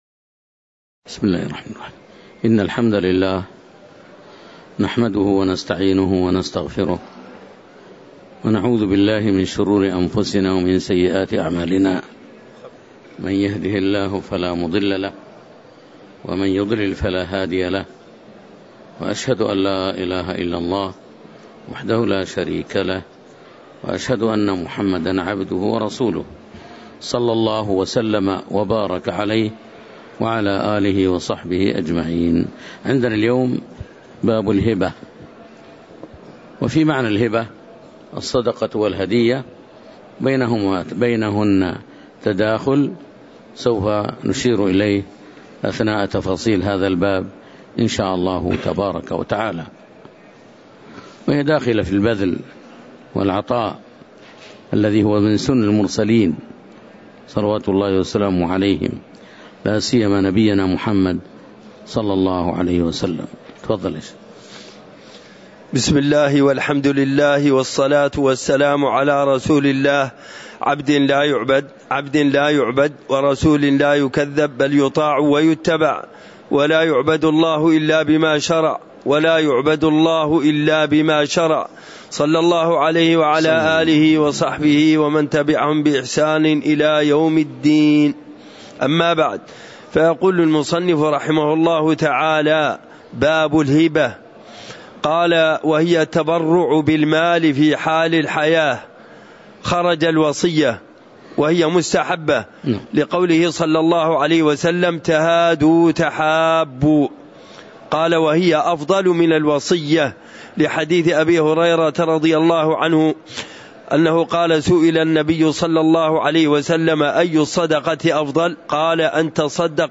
تاريخ النشر ١٩ جمادى الأولى ١٤٤٤ هـ المكان: المسجد النبوي الشيخ: فضيلة الشيخ د. صالح بن سعد السحيمي فضيلة الشيخ د. صالح بن سعد السحيمي قوله: باب الهبة (09)